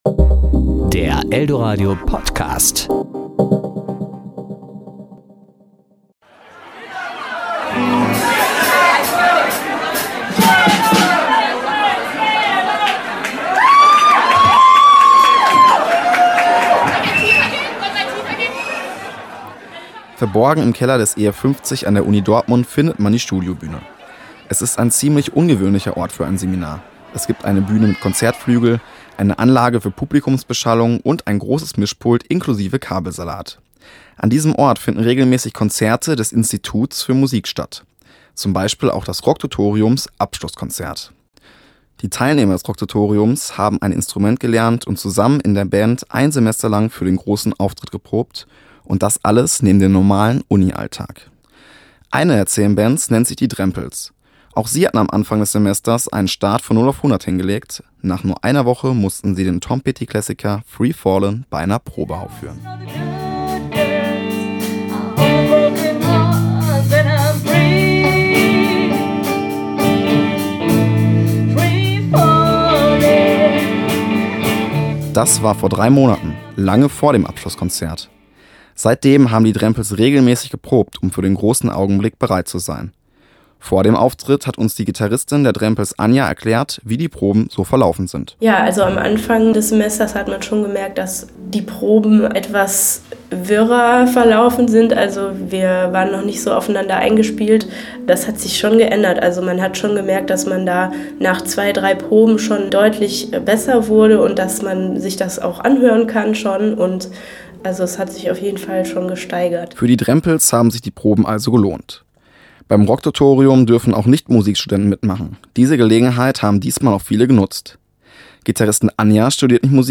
Jetzt besuchte er das Abschlusskonzert aller zehn Bands des Rock-Tutoriums